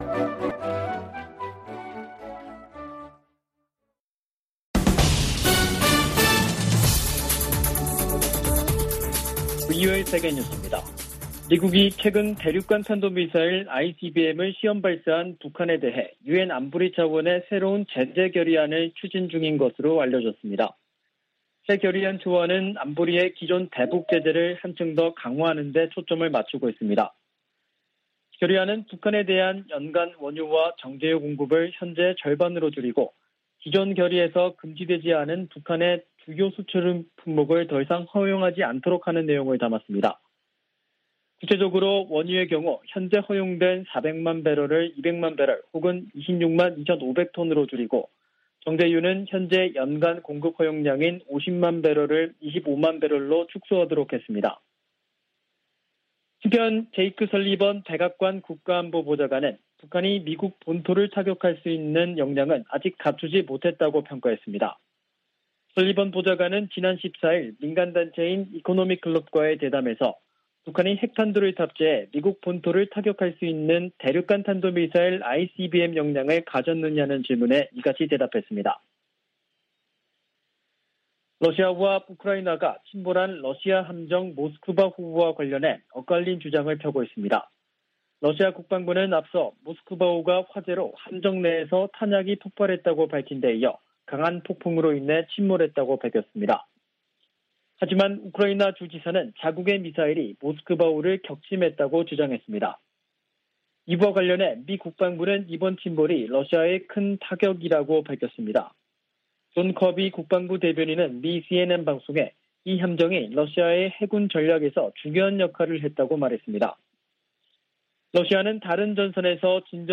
VOA 한국어 간판 뉴스 프로그램 '뉴스 투데이', 2022년 4월 15일 3부 방송입니다. 미국의 새 대북 제재 결의안에서 원유와 정제유 공급 상한선을 절반으로 줄이는 등의 내용이 확인됐습니다. 러시아는 한반도 정세를 악화시키는 어떤 조치에도 반대한다며 미국이 추진 중인 새 안보리 대북 결의에 반대 입장을 밝혔습니다. 북한은 아직 미국을 타격할 수 있는 핵탄두 탑재 ICBM 역량을 보유하지 못했다고 백악관 국가안보보좌관이 밝혔습니다.